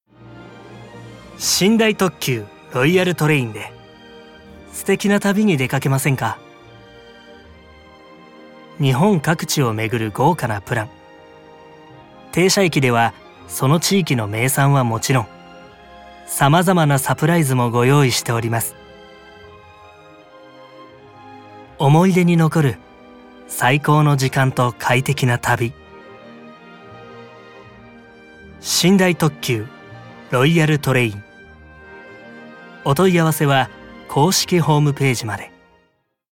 ボイスサンプル
1.落ち着いた・ナチュラル(TVCM)(2025)